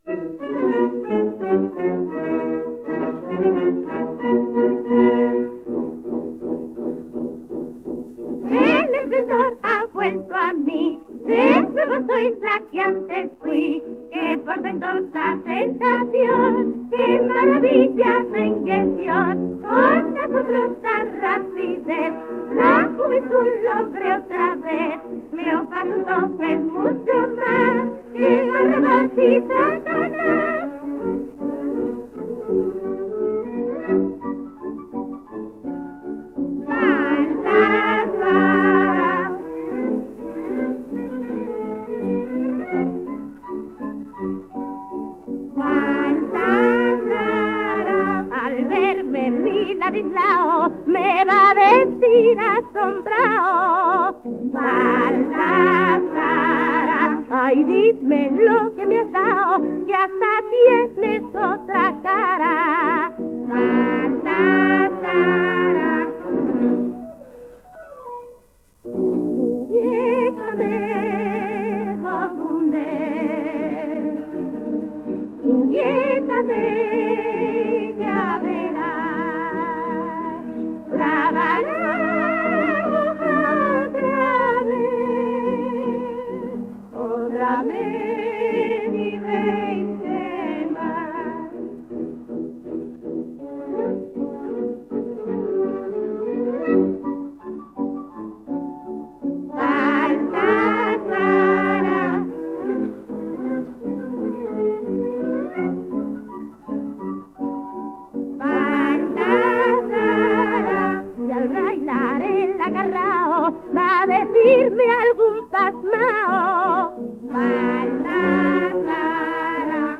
coro [78 rpm